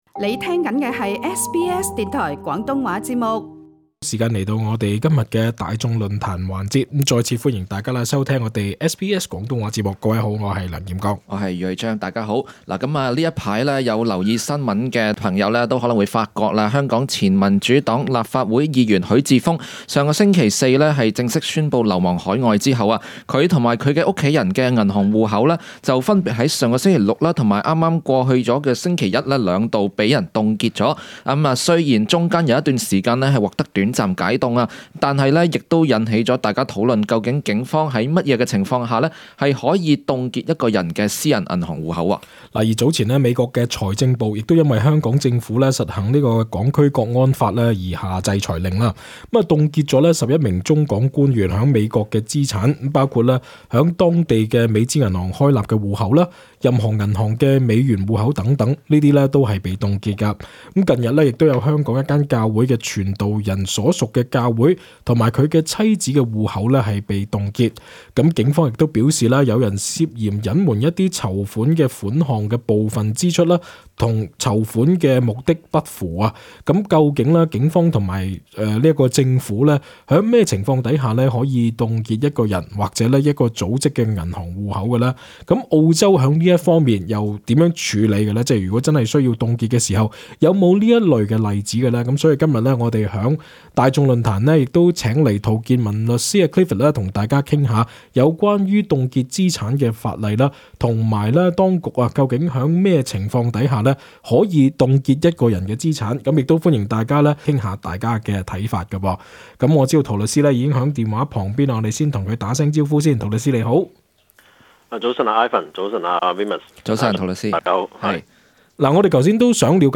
並邀請聽眾一起討論情況